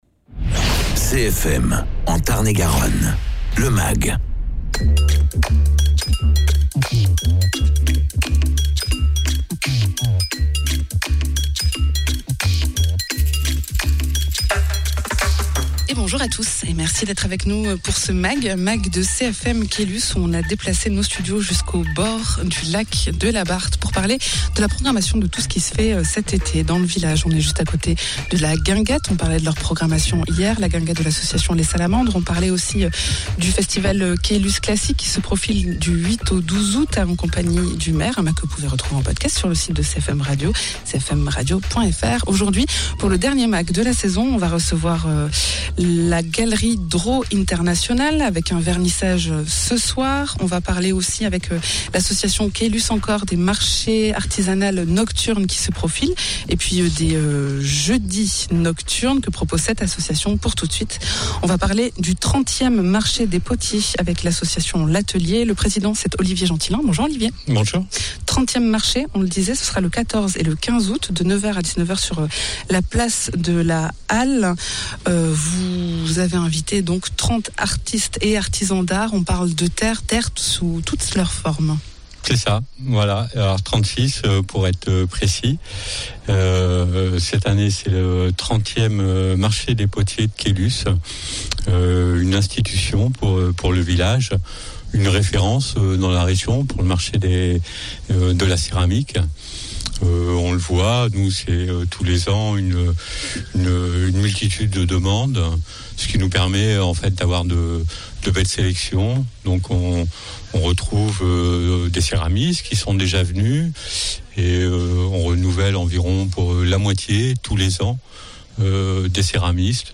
Un mag en extérieur au bord du lac de Caylus à propos du 30ème marché des potiers de Caylus ce 14 et 15 Août où on attend 36 exposants.